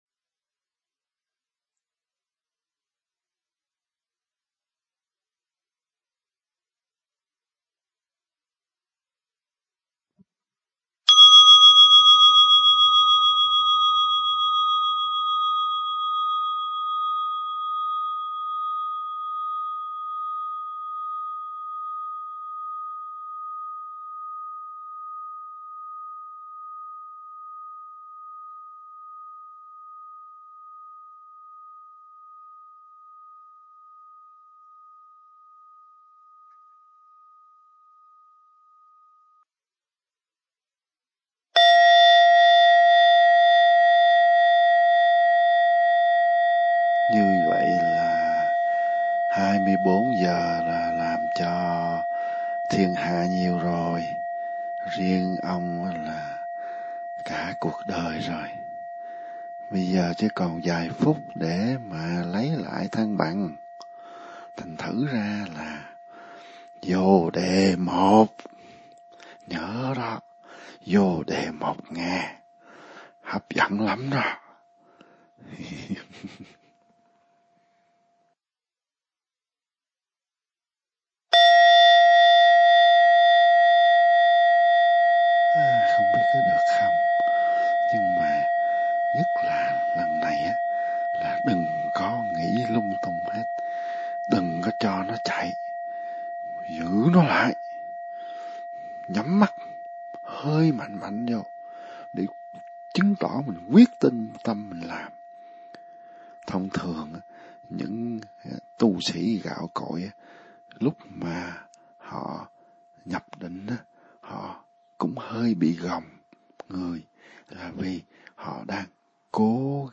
Cứ 1 phút rưởi là một tiêng chuông (tổng cộng là 15 tiếng) trầm hơn: Sakya (xa-ky-a)
Và kết thúc buổi tập bằng ba tiếng chuông trầm nhất: Zhada (za-đa)
Đây là một bản audio mẫu, test với lời nhắn của Thầy.